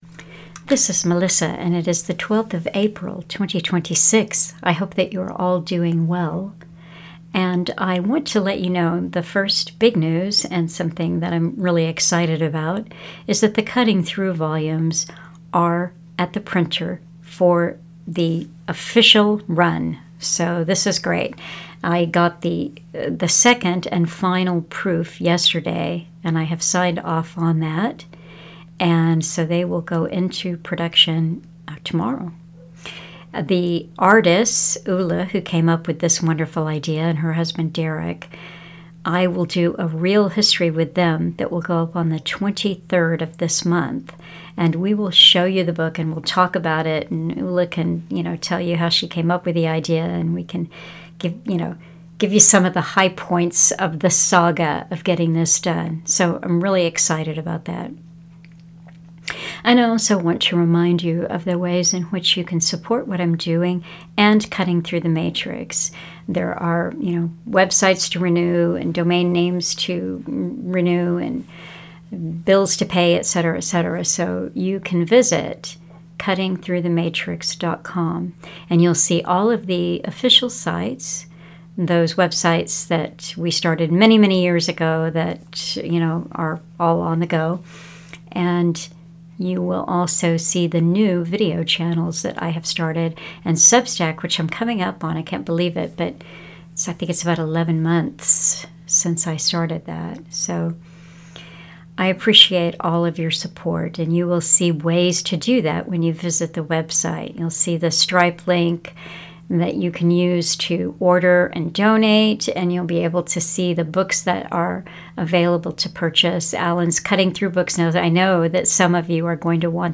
Educational Talk